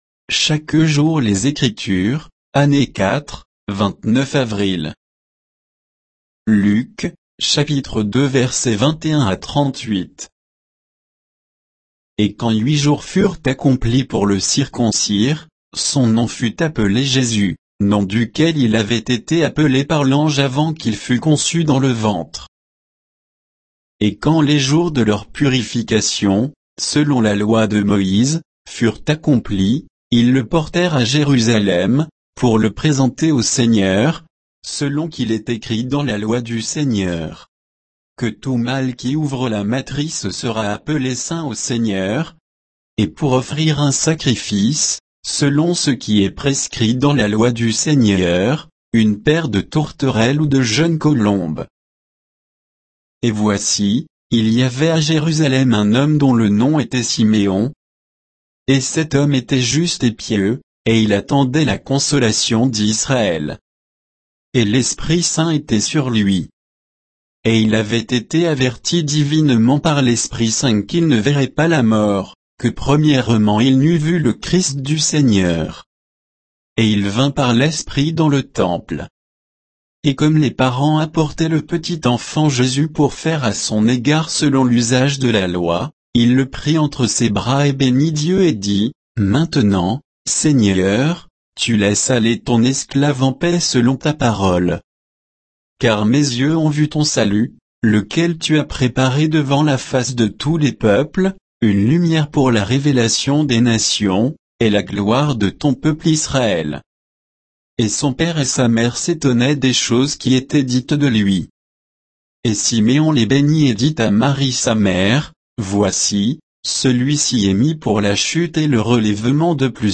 Méditation quoditienne de Chaque jour les Écritures sur Luc 2